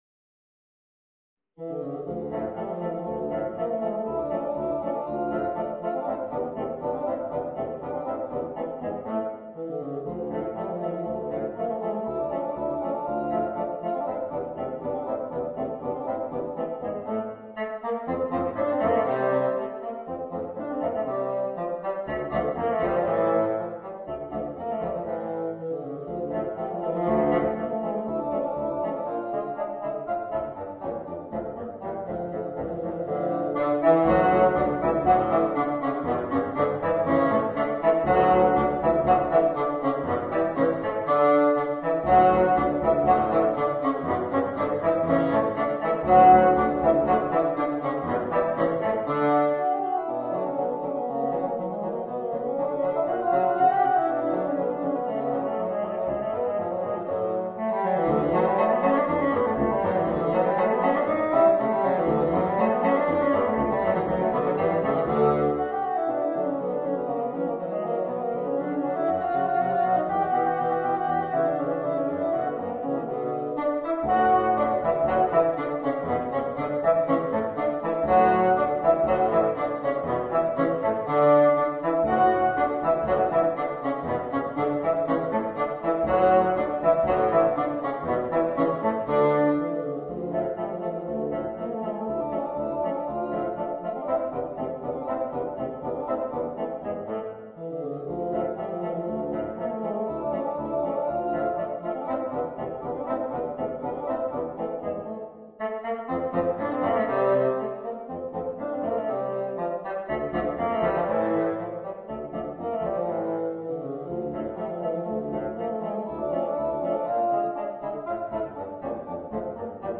Per 4 fagotti